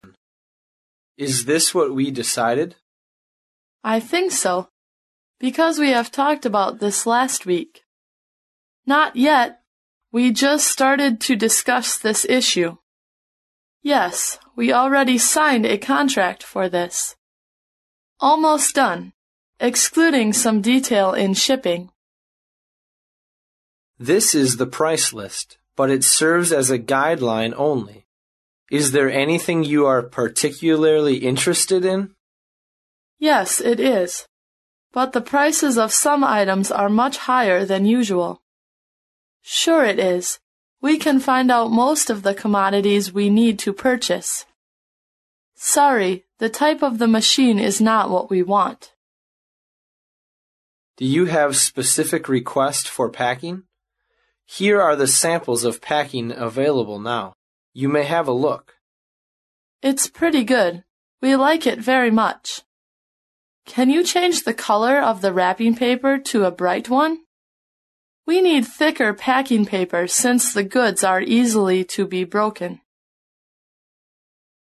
欢迎使用本站免费为您提供的外贸英语口语学习教程,教程涉及到英语商贸活动的多个方面.为了便于不同程度的英语爱好者学习,除提供英语会话音频外,还提供了相应话题的文本资料。